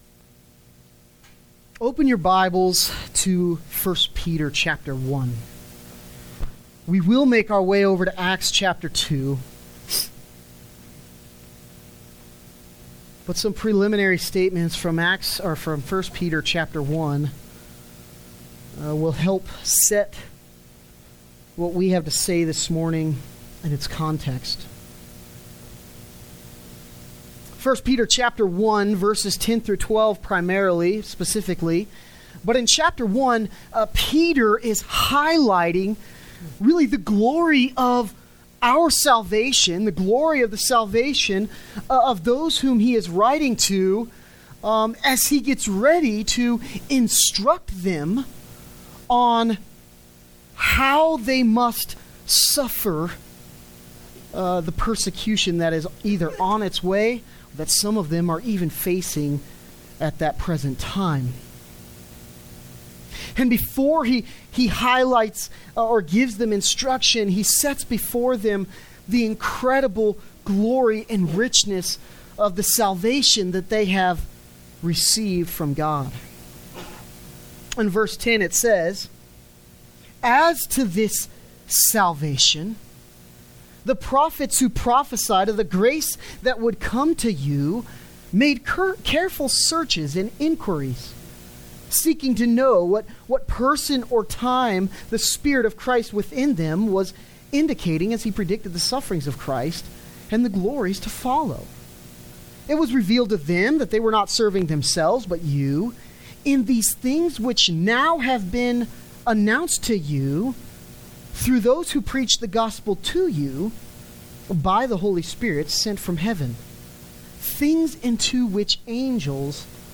Sermon from Aug 12